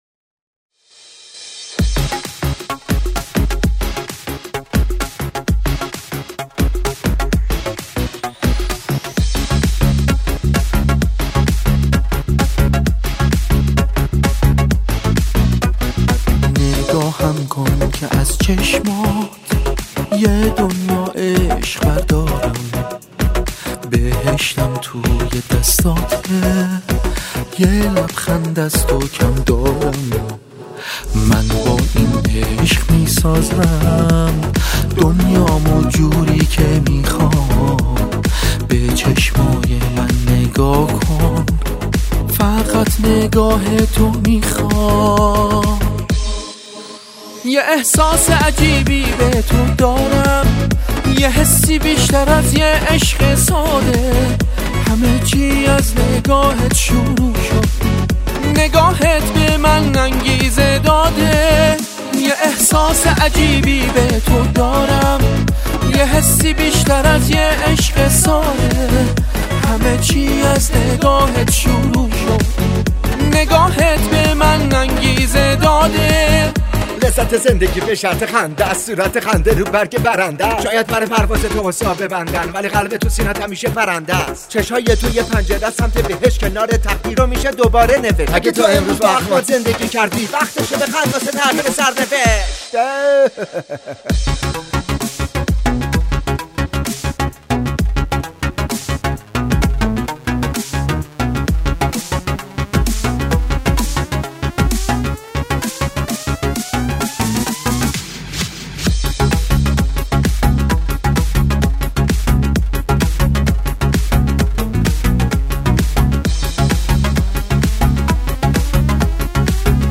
در استودیو مهرآوا